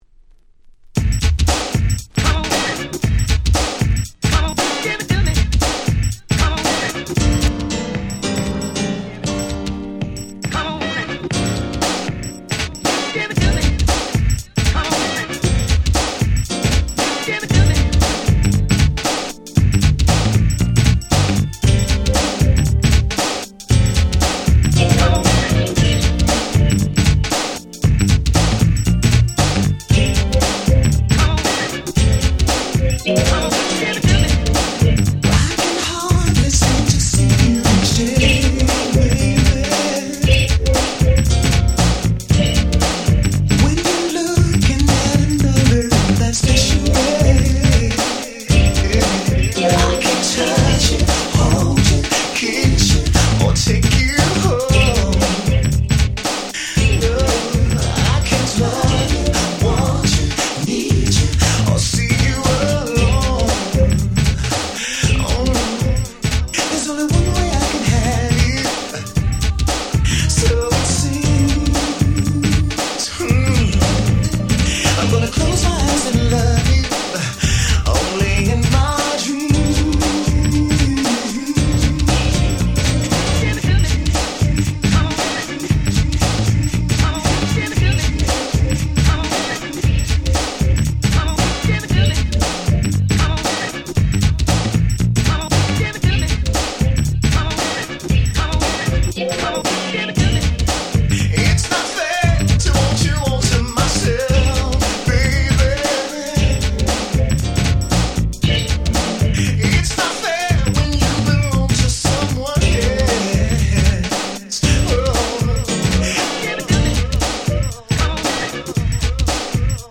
89' Nice R&B / Ground Beat / New Jack Swing !!